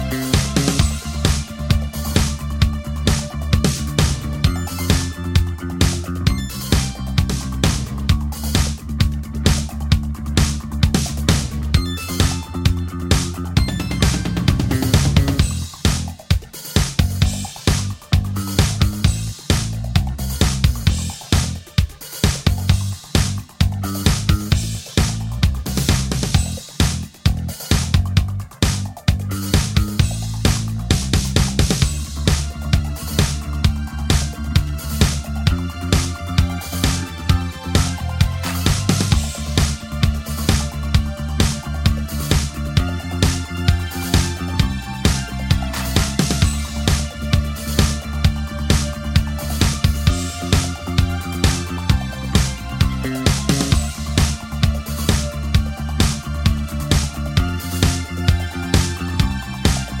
Minus Main Guitar For Guitarists 4:08 Buy £1.50